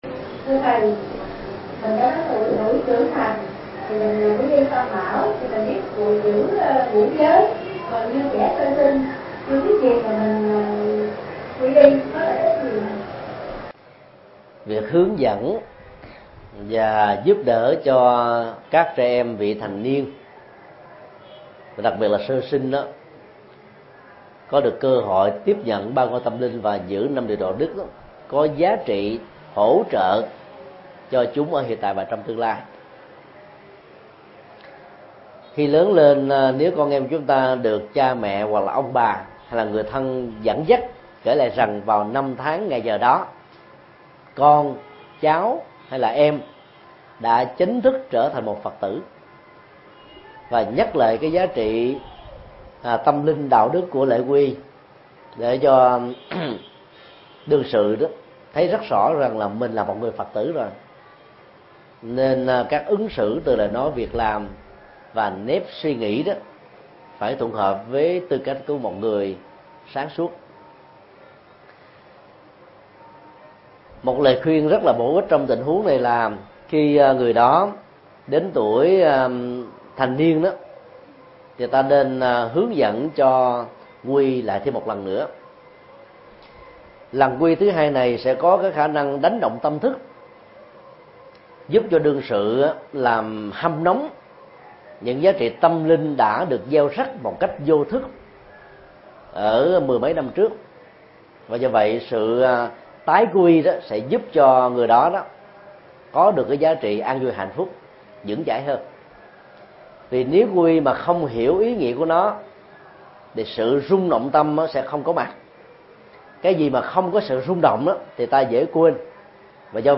Vấn đáp: Qui y tam bảo cho trẻ – Thích Nhật Từ